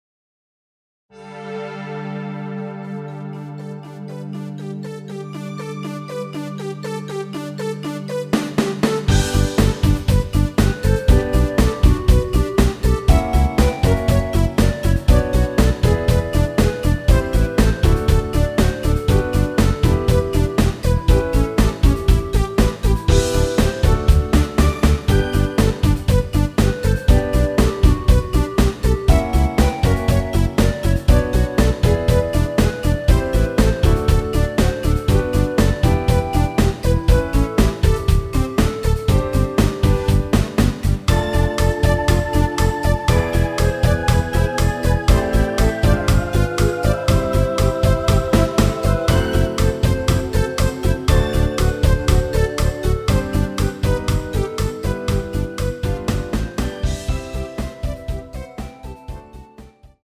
원키 멜로디 포함된 MR 입니다.(미리듣기 참조)
엔딩이 페이드 아웃이라 가사의 마지막 까지후 엔딩을 만들어 놓았습니다.!
앞부분30초, 뒷부분30초씩 편집해서 올려 드리고 있습니다.
중간에 음이 끈어지고 다시 나오는 이유는